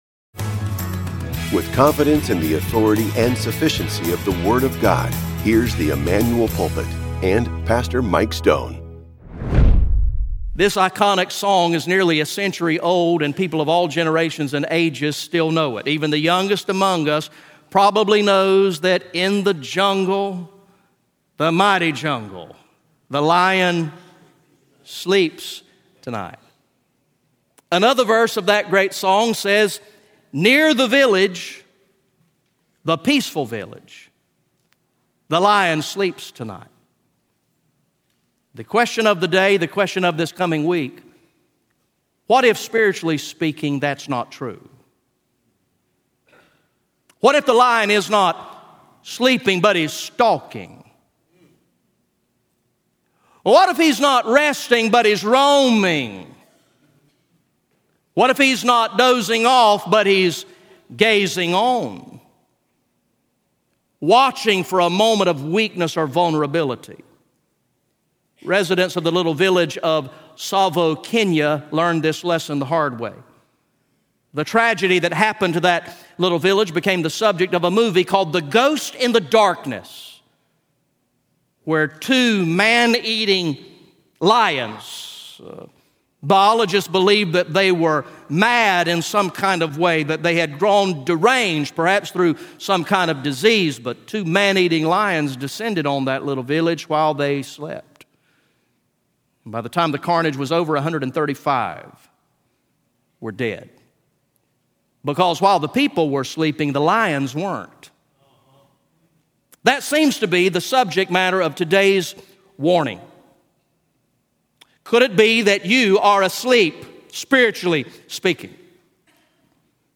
The teaching ministry